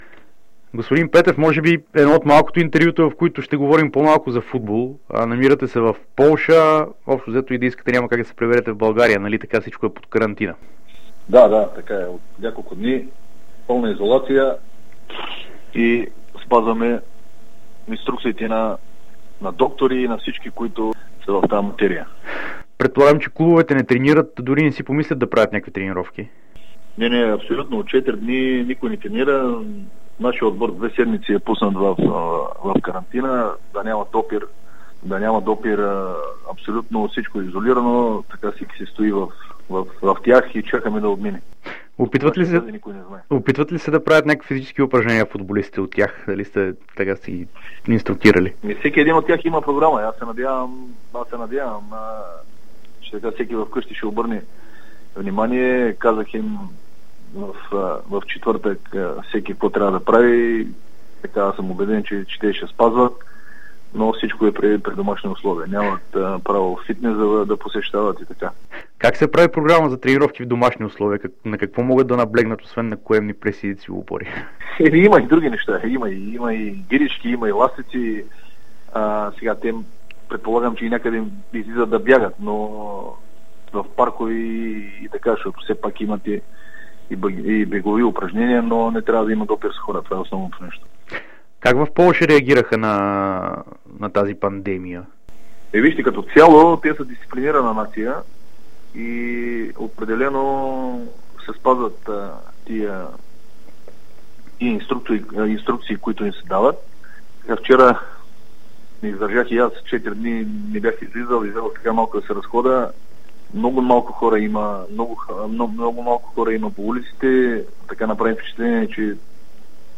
Той говори за Дарик радио и dsport от град Бялисток, където е в момента. Той разказа как хората там приемат случващото се с пандемията.